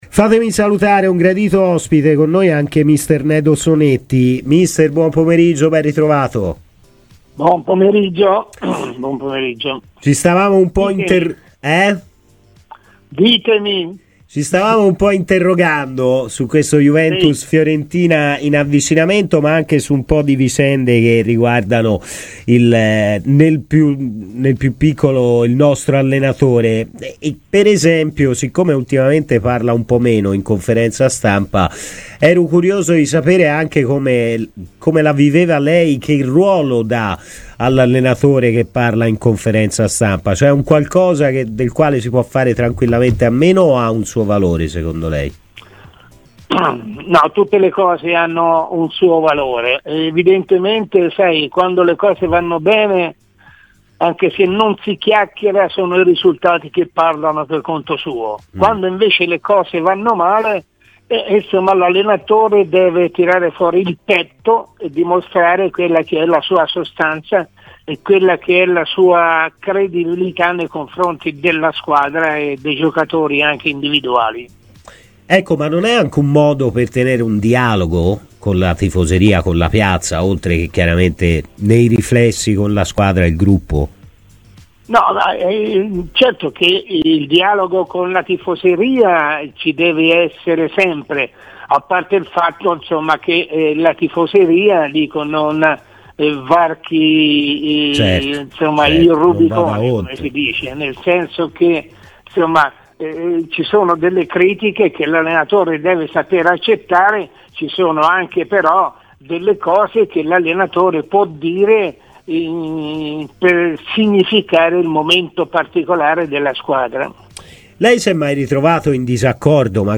L'ex allenatore Nedo Sonetti ha parlato così a Radio Firenzeviola: "Tutte le cose hanno un valore ma quando le cose vanno bene sono i risultati che parlano, quando invece vanno male un tecnico dovrebbe tirare fuori il petto per tirare fuori il meglio dai suoi giocatori.